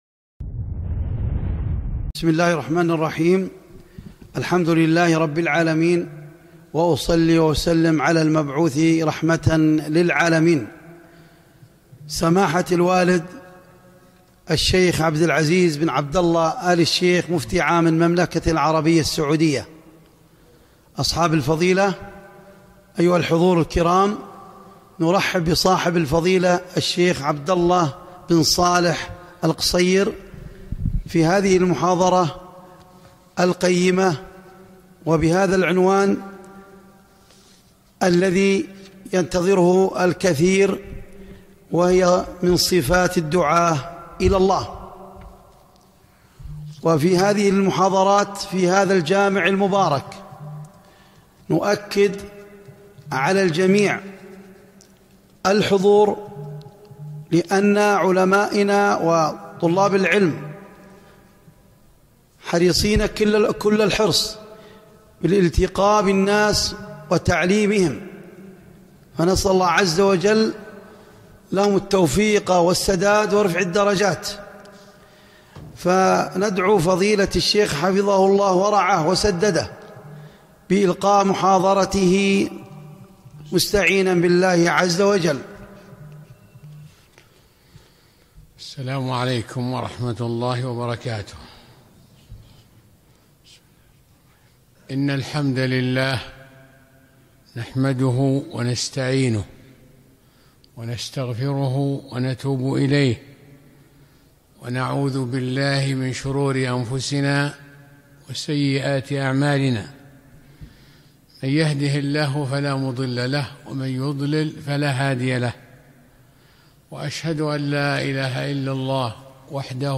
محاضرة - من صفات الدعاة الى الله